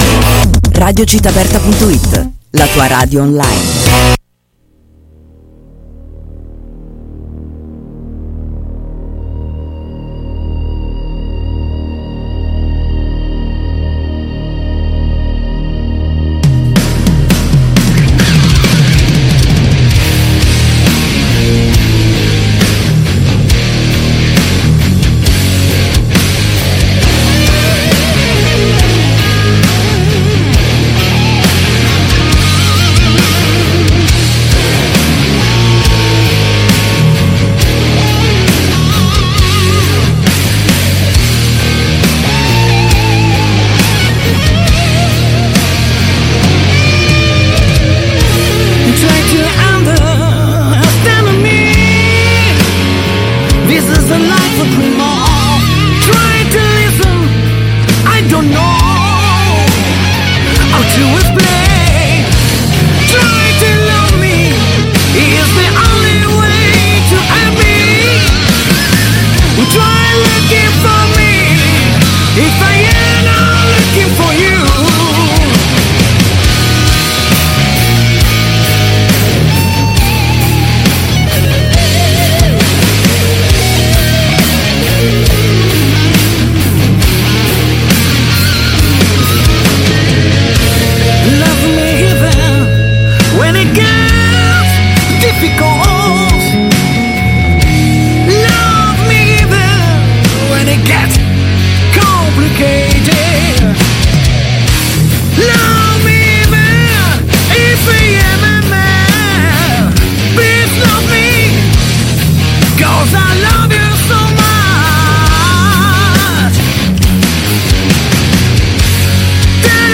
intervista-rodsacred-5-6-23.mp3